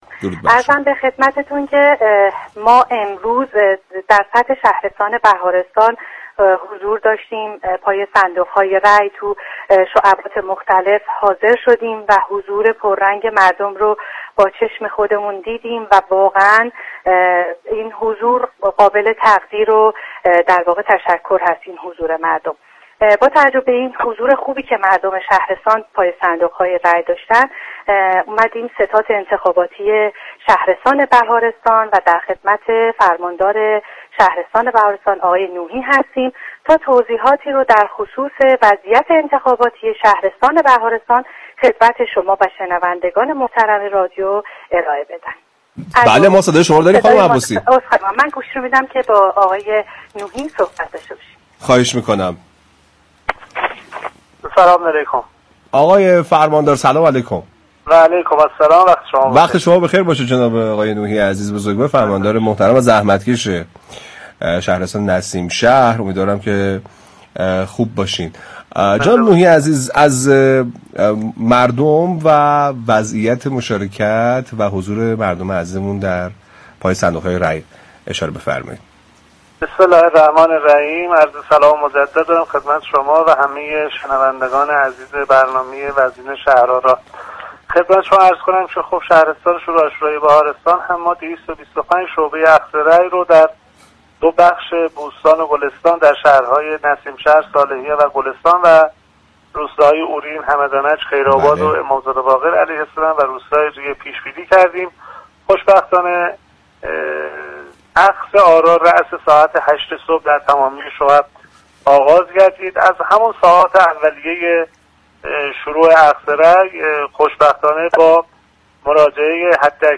به گزارش پایگاه اطلاع رسانی رادیو تهران، عبدالله نوحی فرماندار شهرستان بهارستان در گفت و گو با ویژه برنامه انتخاباتی «شهرآرا» اظهار داشت: با توجه به تراكم و تركیب جمعیتی شهرستان، 223 شعبه ثابت و 2 شعبه سیار برای اخذ رأی در شهرستان بهارستان در نظر گرفته شده است. 102 شعبه در بخش بوستان و 123 شعبه در گلستان پیش‌بینی شده است.